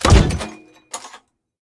tank-door-close-2.ogg